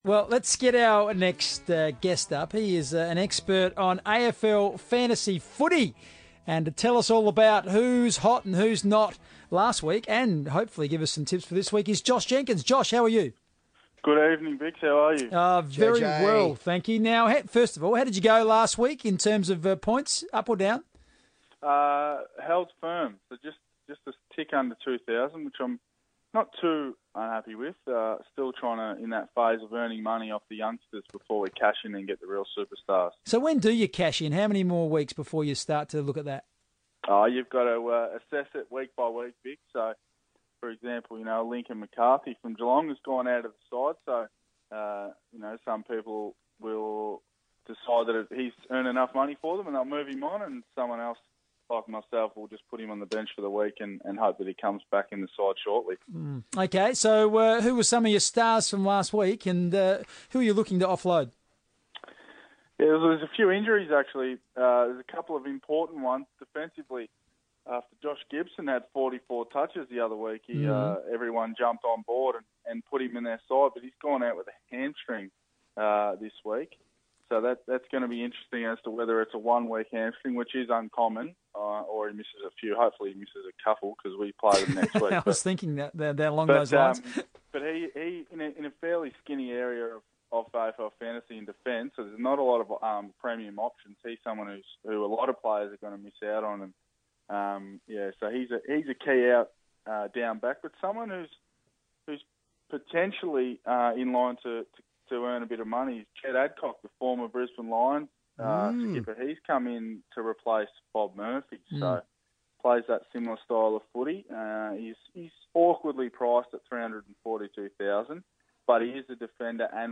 Crows forward Josh Jenkins reveals his AFL Fantasy tips on FIVEaa radio heading into Round Four